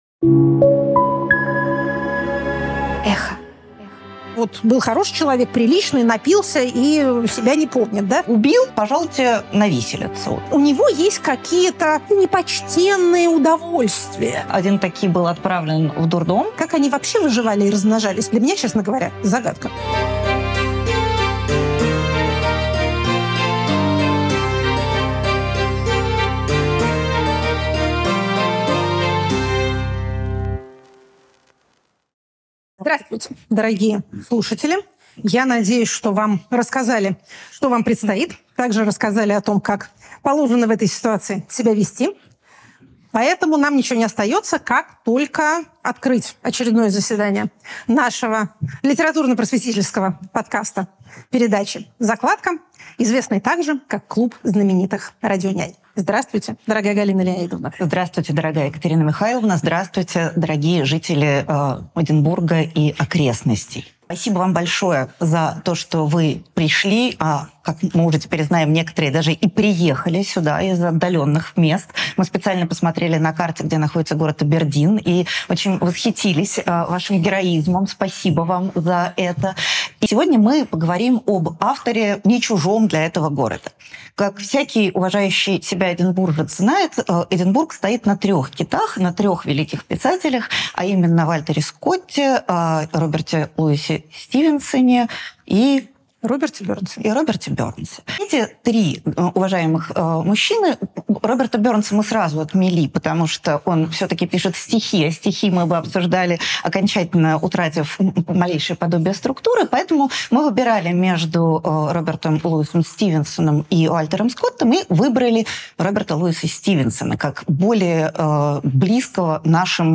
Екатерина Шульман политолог Галина Юзефович литературный критик